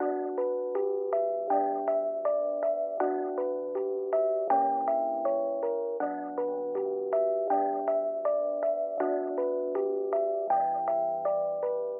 描述：两块聚苯乙烯手机摩擦的麦克风样本类似于合成帽子的声音
Tag: 命中 冲击 聚苯乙烯 样品